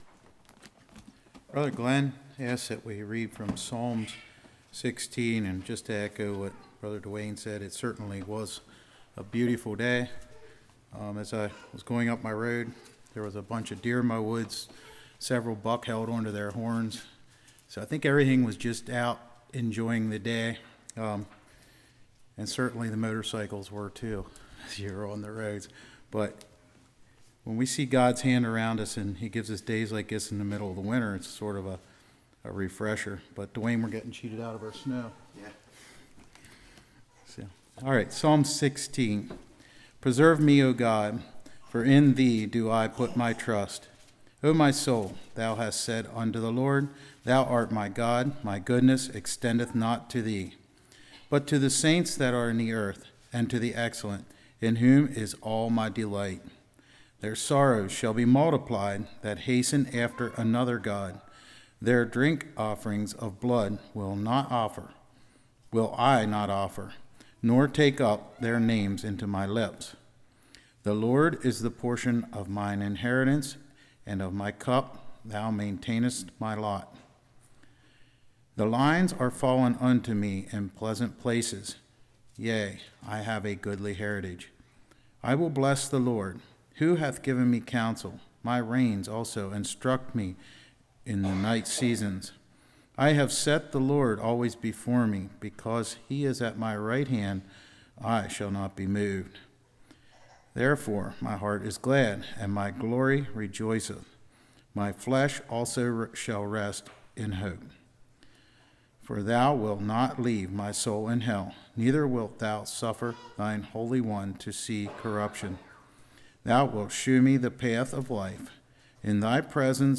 Psalms 16:1-11 Service Type: Evening Psalm 16:1-7 Attitudes to God’s people Inheritace of Jesus « Eternal Security